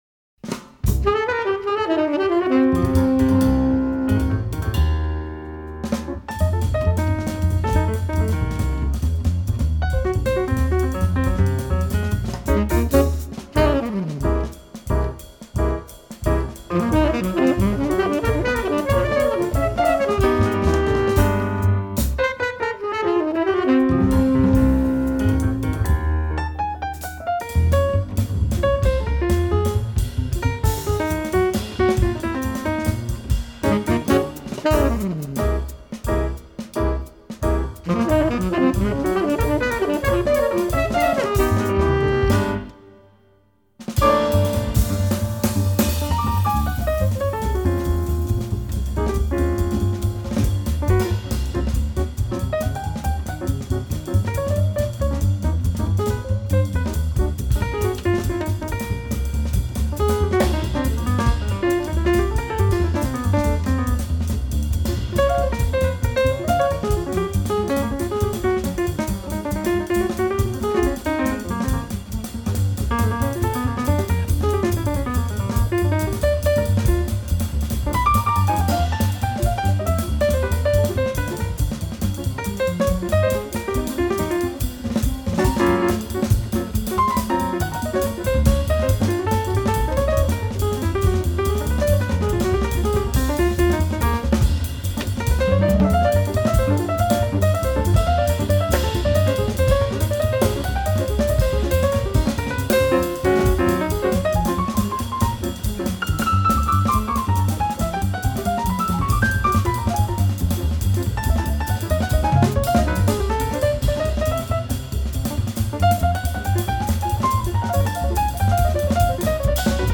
piano
bass
drums
saxophone
tabla, percussion